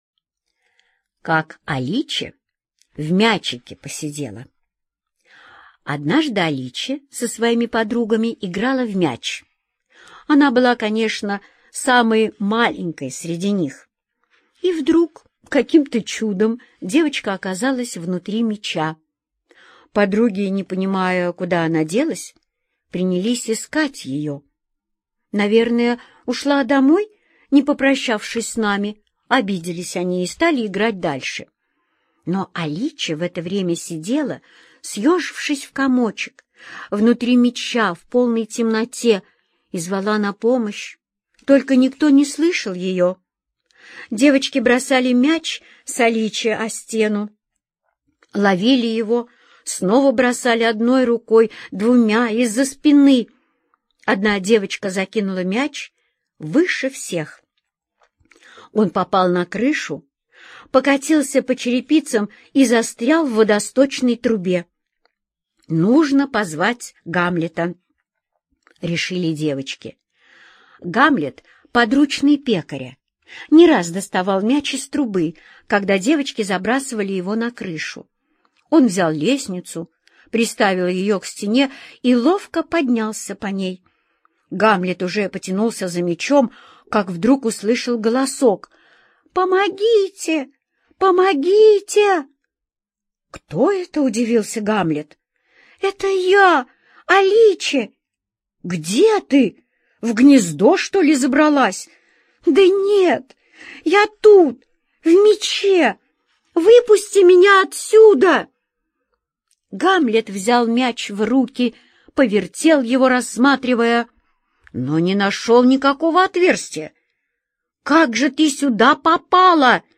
Слушайте Как Аличе в мячике посидела - аудиосказку Родари Д. Как-то Аличе оказалась внутри мяча и никак не могла выбраться оттуда.